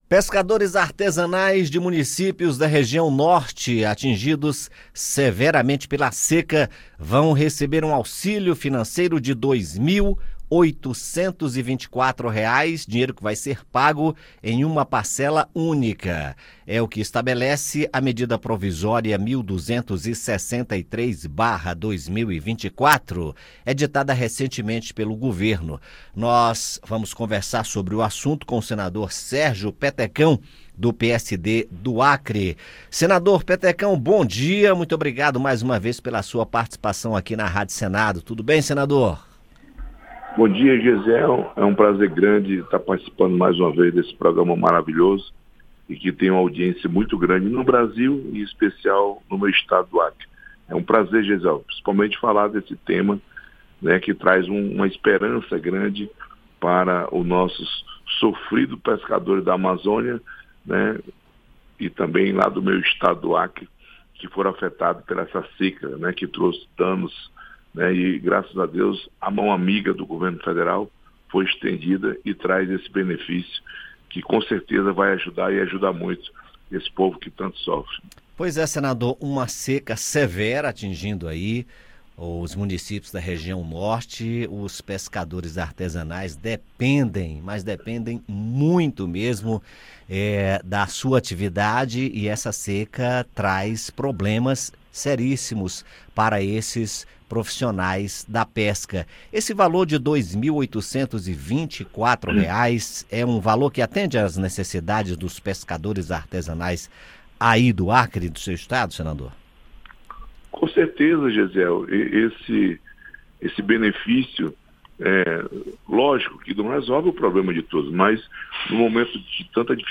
O senador Sérgio Petecão (PSD-AC) explica o benefício e como o dinheiro vai chegar aos pescadores. Petecão destaca soluções e medidas que podem ser adotadas no longo prazo para prover apoio financeiro às famílias que se encontram em situação de vulnerabilidade devido às mudanças climáticas.